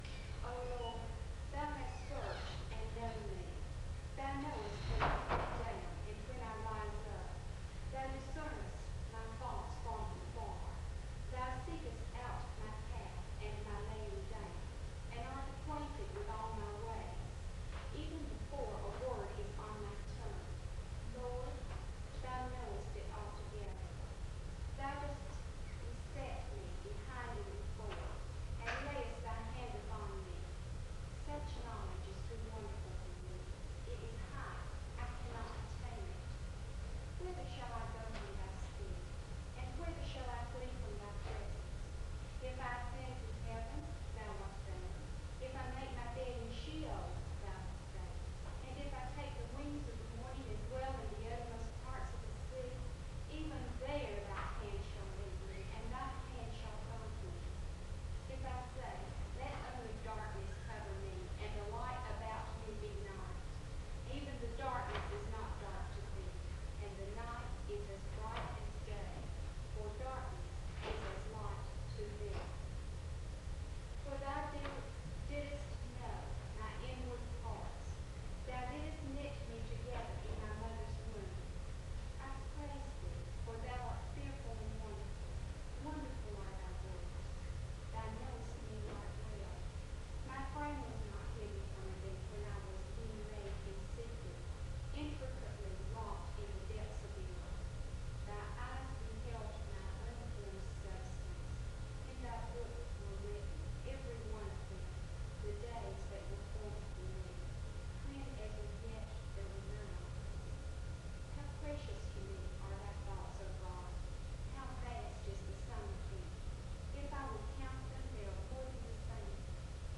The service begins with a Scripture reading from Psalm 139 (00:00-02:46).
The speaker leads the audience in a responsive reading (02:47-04:36). Another speaker reads from John 15 (04:37-06:20).